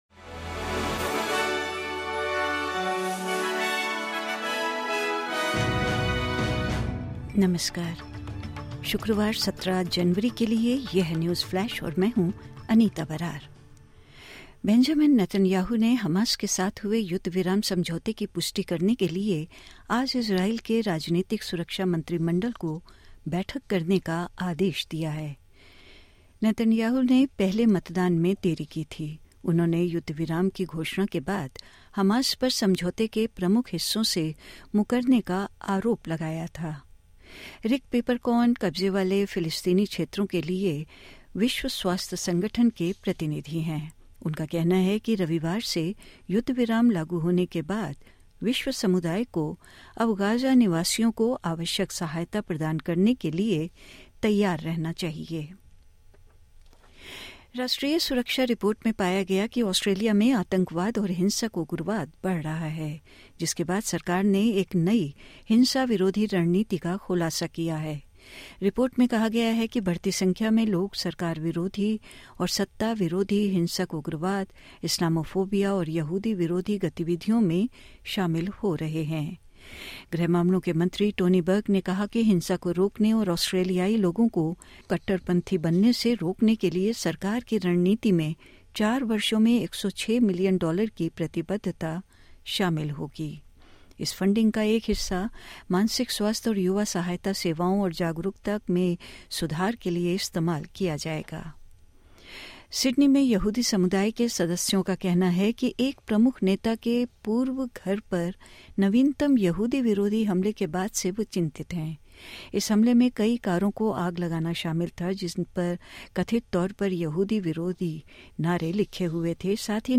सुनें 17/01/2025 की प्रमुख खबरें ऑस्ट्रेलिया और भारत से हिन्दी में।